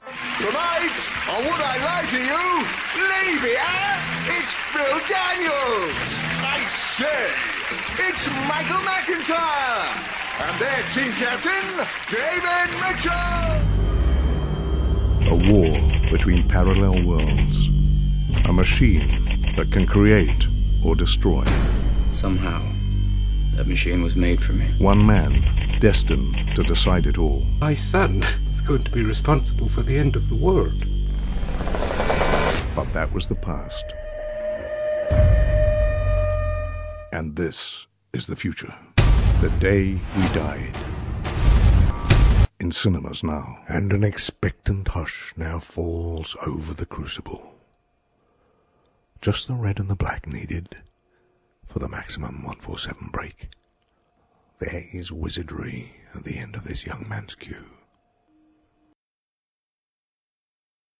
Promo Reel
RP ('Received Pronunciation')
Promo, Cool, Energetic, Confident, Bold